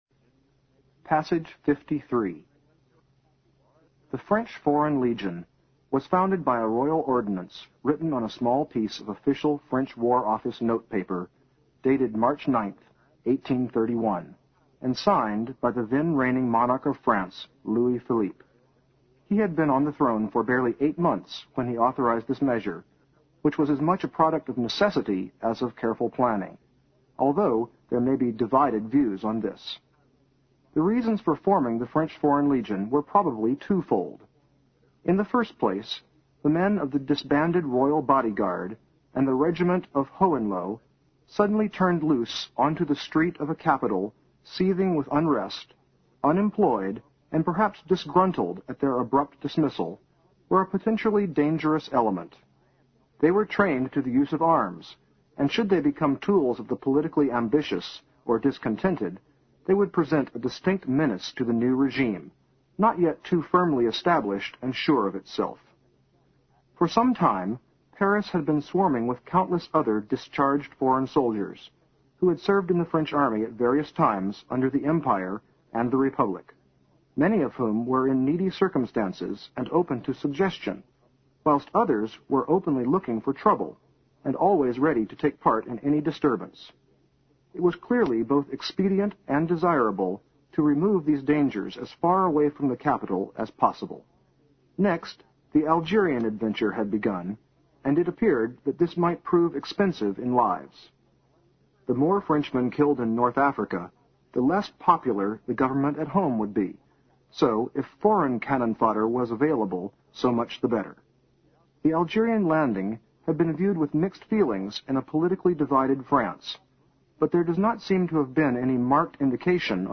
新概念英语85年上外美音版第四册 第53课 听力文件下载—在线英语听力室